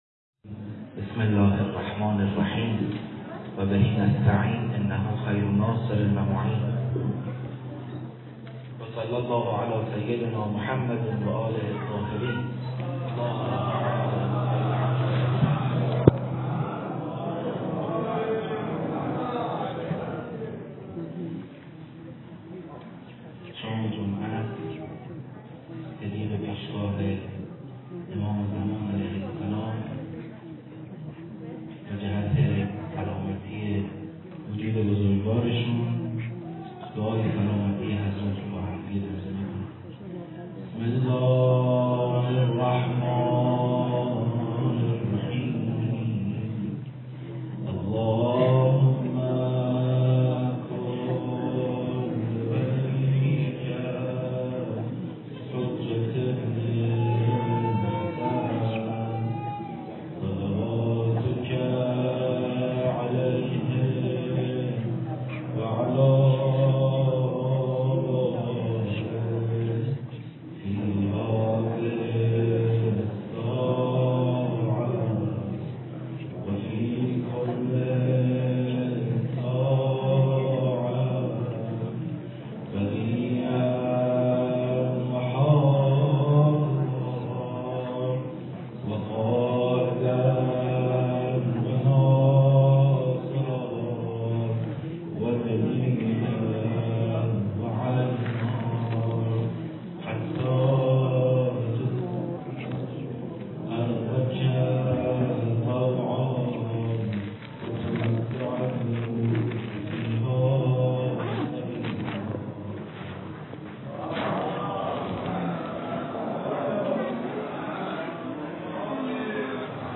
🏴 سخنرانی به مناسبت ایام شهادت حضرت فاطمه سلام‌الله‌علیها
🕌 تهران، مسجد سیدالشهدا علیه‌السلام